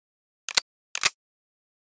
pistol_magcheck.ogg